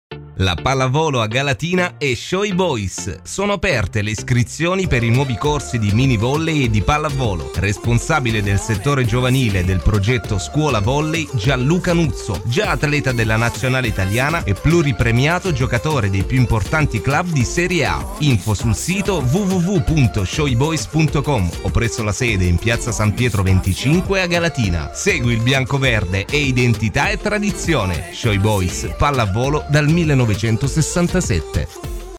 Il promo in onda su Radio Orizzonti Activity per l’apertura delle iscrizioni ai corsi di minivolley e pallavolo:
spot1-radio.mp3